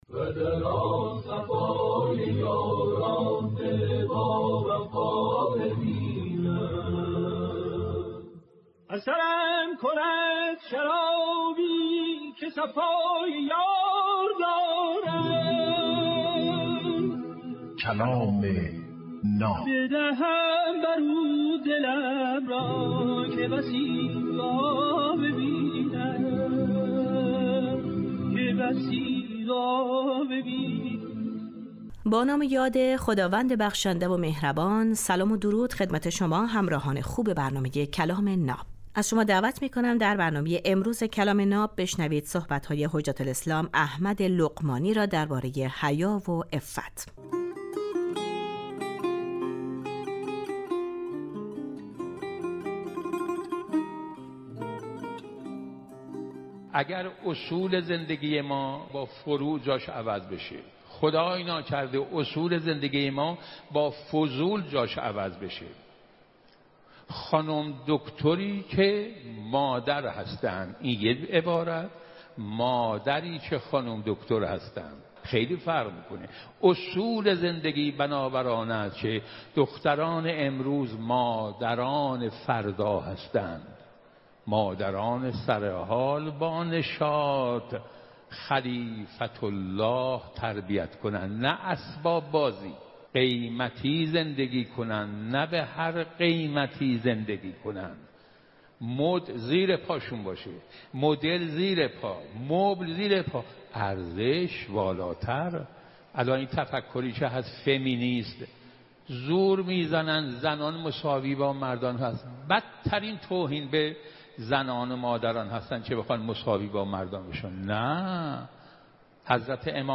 در این برنامه هر روز یک سخنرانی آموزنده کوتاه پخش می شود.